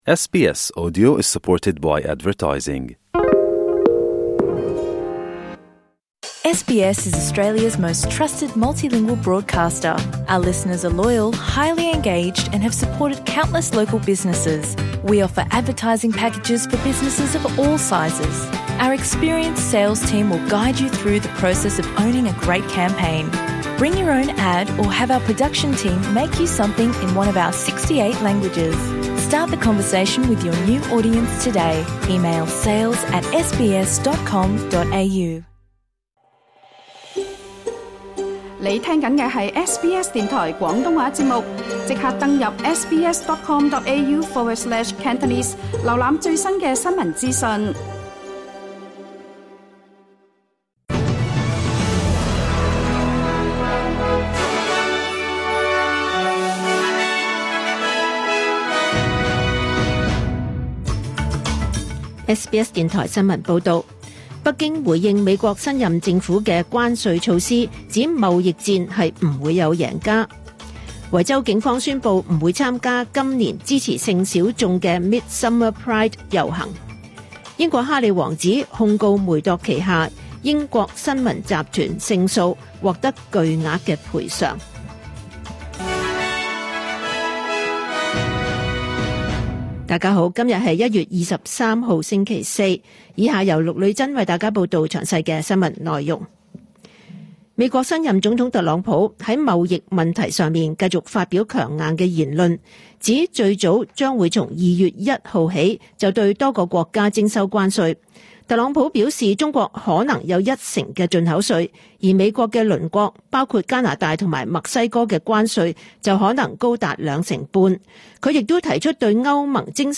SBS廣東話新聞（2025 年 1 月 23 日） Play 12:04 SBS廣東話新聞報道 SBS廣東話節目 View Podcast Series 下載 SBS Audio 應用程式 其他收聽方法 Apple Podcasts  YouTube  Spotify  Download (11.05MB)  2025 年 1 月 23 日 SBS 廣東話節目詳盡早晨新聞報道。 新聞提要 北京回應美國新任政府嘅關稅措施，指貿易戰係唔會有贏家。 維州警方宣佈唔會參加今年嘅 Midsumma Pride 支持性小眾遊行。 英國哈里王子控告梅鐸旗下英國新聞集團勝訴獲得巨額賠償。